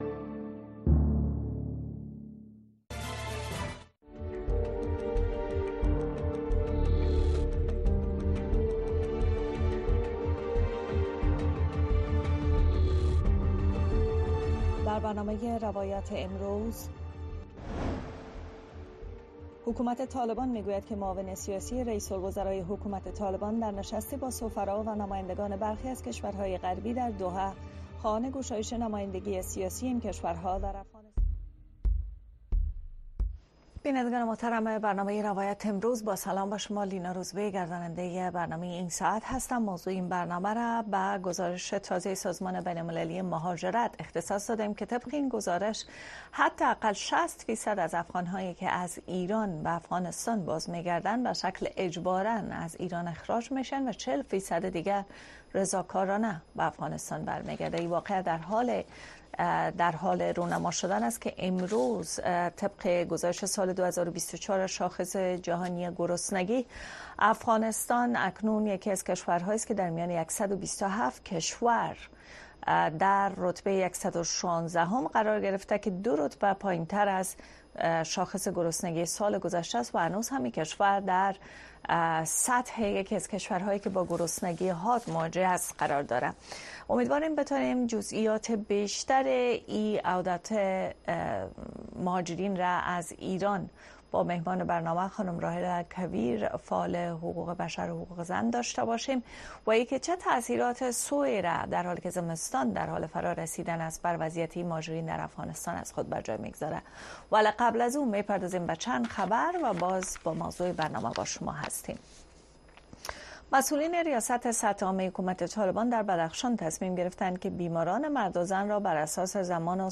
در برنامۀ روایت امروز شرح وضعیت در افغانستان را از زبان شرکت کنندگان این برنامه می‌شنوید. این برنامه شب‌های یک‌شنبه، دوشنبه، سه‌شنبه و پنج‌شنبه از ساعت ٩:۰۰ تا ۹:۳۰ شب به گونۀ زنده صدای شما را در رادیو و شبکه‌های ماهواره‌ای و دیجیتلی صدای امریکا پخش می‌کند.